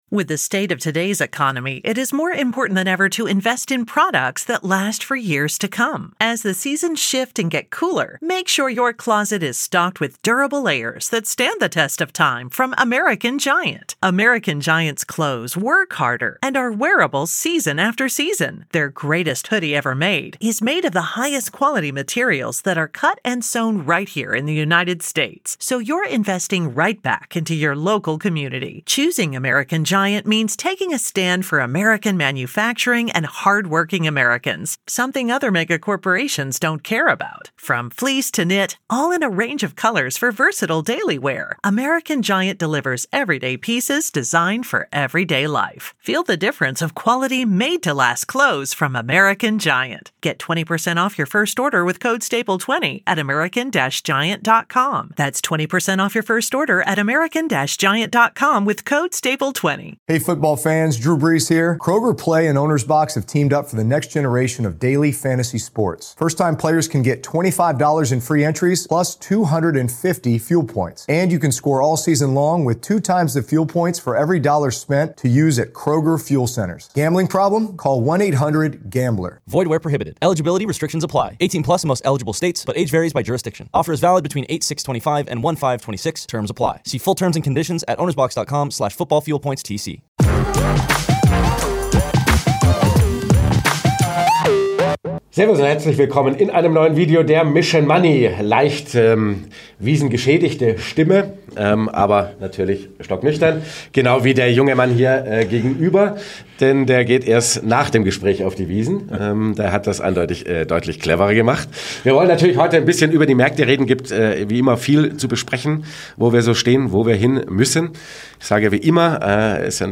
Wichtiger ist, mit Plan investiert zu bleiben, anstatt hektisch zu versuchen, den Markt zu timen. Im Interview mit dem Youtube-Kanal Mission Money erklärt der Value-Investor, warum es nach wie vor attraktiv bewertete Nebenwerte in Europa gibt, er die Finger von China-Aktien lässt und warum es wichtig ist, auf Qualität der Unternehmen zu achten, anstatt nur den Börsen-Hypes hinterherzujagen.